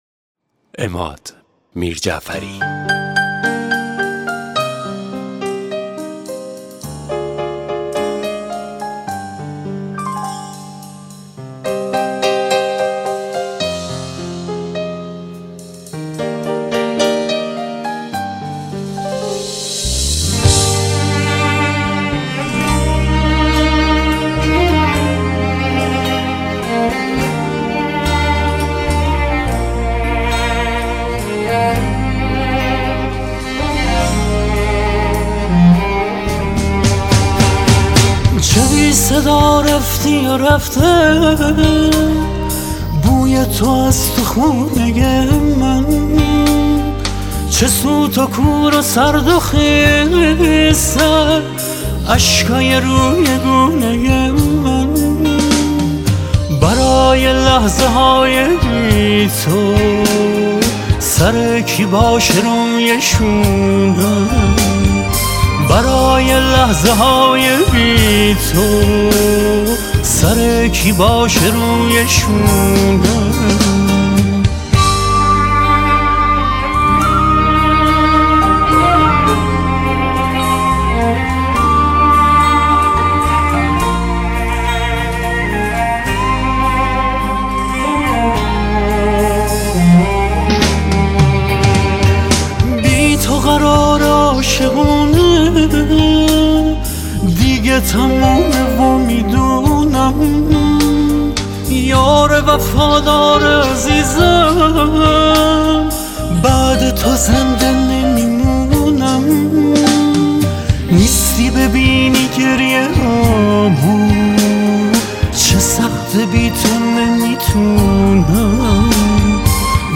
آهنگ فارسی
غمگین
آهنگی در سبک آهنگ های غمگین فارسی